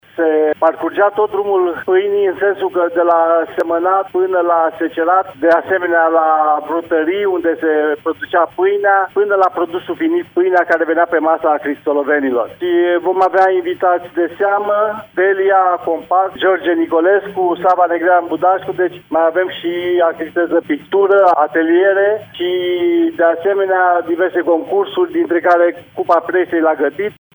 La Cristian de astăzi până duminică sunt sărbătorite Zilele comunei, ocazie cu care are loc Festivalul Drumul Pâinii, despre care ne-a spus mai multe primarul din Cristian, Gicu Cojocaru: